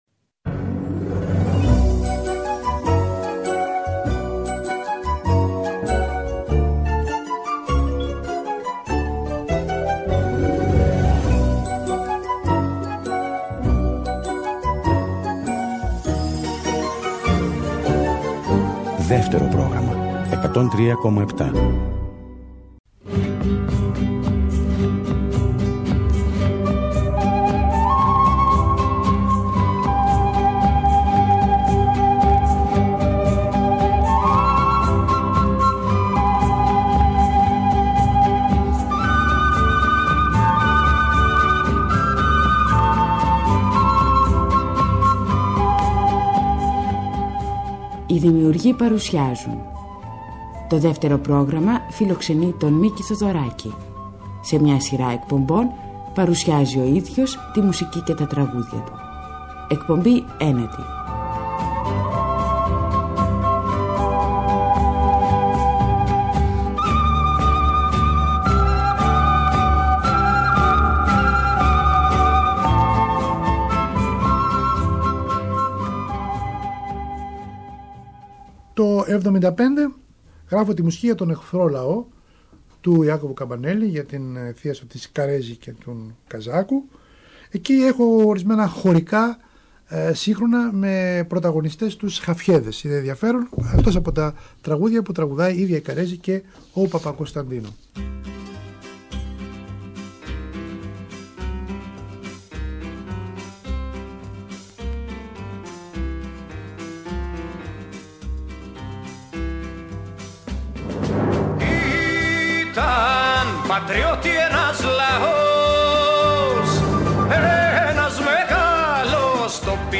τον Μίκη Θεοδωράκη, μόνο μπροστά στο μικρόφωνο να ξετυλίγει με τον δικό του τρόπο το κουβάρι των αναμνήσεών του και να αφηγείται τη ζωή του.
Ο Θεοδωράκης αυτοβιογραφείται